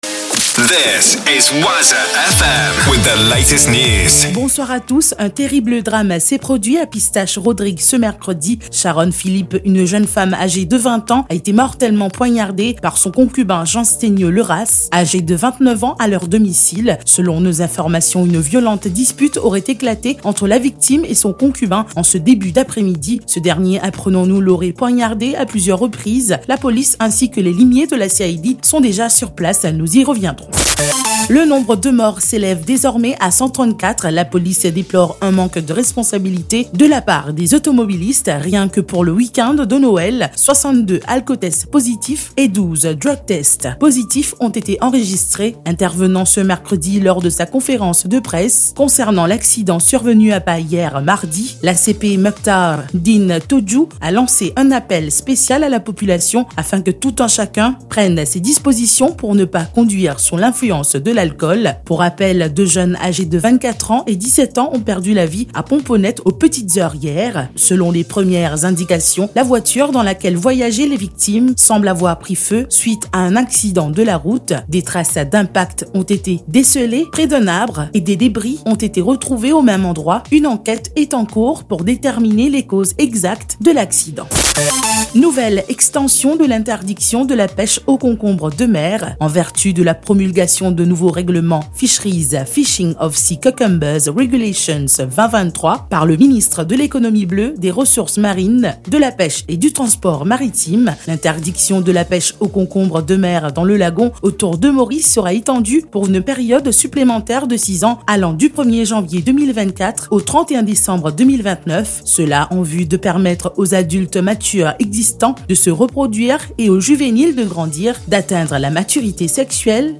NEWS 17H - 27.12.23